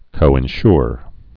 (kōĭn-shr)